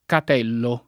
kat$llo] s. m. — latinismo lett. per «cucciolo» — sim. il pers. m. stor. Catello, anc÷ra in uso in Camp. (col femm. Catella, anche personaggio del Boccaccio), e i cogn. Catella, Catelli, Catello